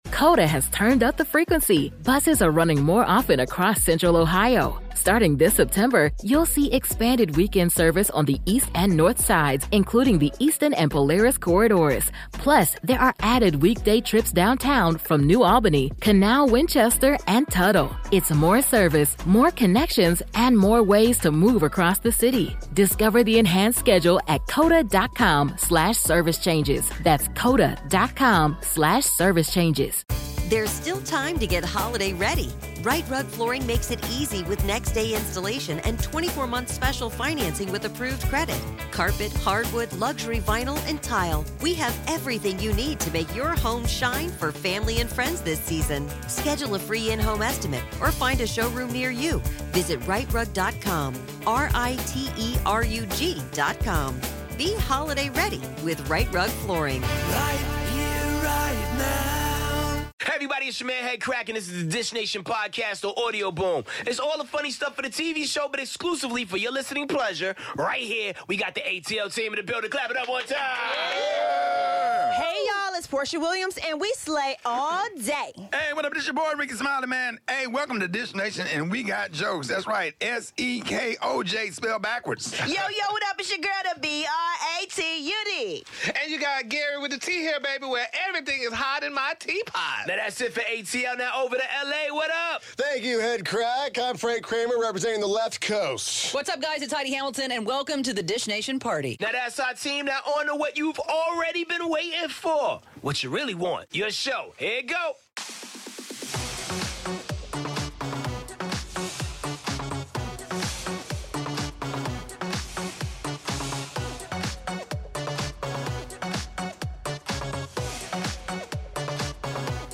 Jennifer Aniston is a Goddess, Jennifer Lopez is hustlin' for a Oscar and Nick Jonas has a smokin' cover on today's Dish Nation. 'Married to Medicine's Quad Webb-Lunceford is in studio with us so tune IN!